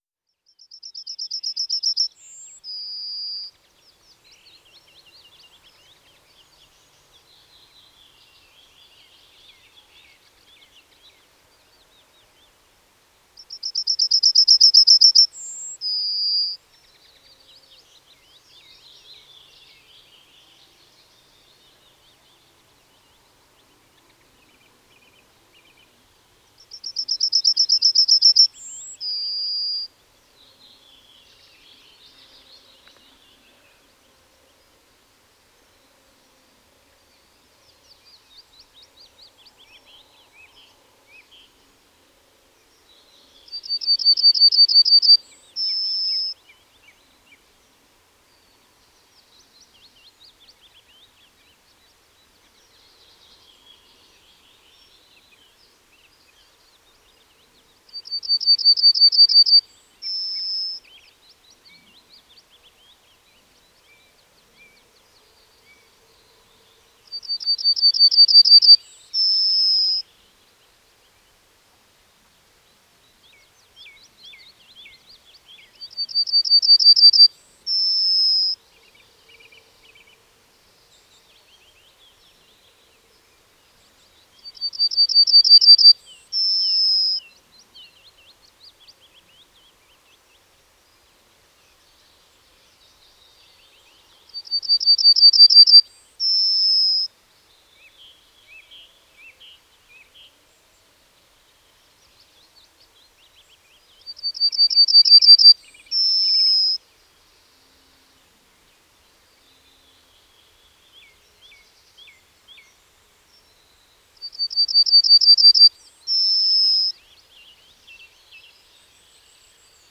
Goldammer Gesang
Goldammer-Ruf-Voegel-in-Europa.wav